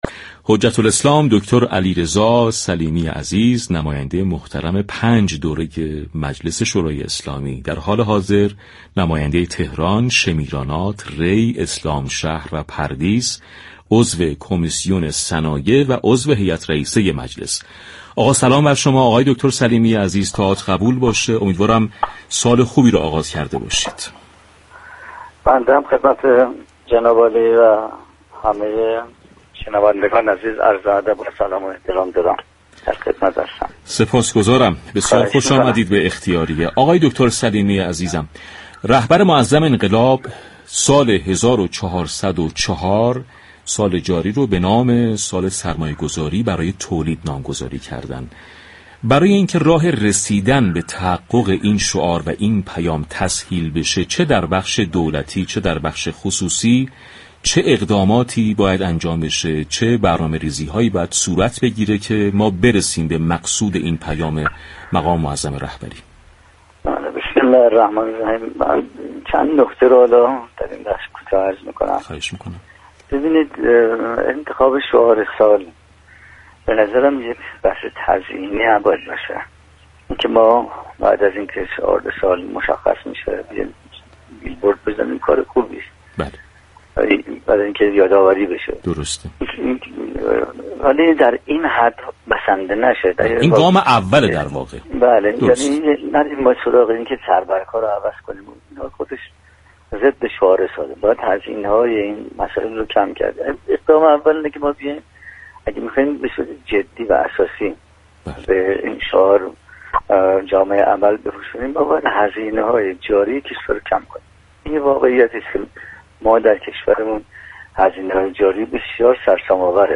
برای تحقق شعار سال، باید هزینه‌های جاری كشور را مدیریت كنیم به گزارش پایگاه اطلاع رسانی رادیو تهران؛ حجت الاسلام علیرضا سلیمی نماینده مردم تهران در مجلس شورای اسلامی و عضو هیات رئیسه مجلس در گفت و گو با ویژه برنامه «اختیاریه»، در خصوص تبیین شعار سال «سرمایه‌گذاری برای تولید» و موانع موجود بر سر راه تولیدكنندگان و سرمایه‌گذاران اظهار داشت: در گام اول باید برای تحقق شعار سال هزینه‌های جاری كشور را مدیریت كنیم در كشور ما هزینه‌های جاری بسیار سرسام آور است.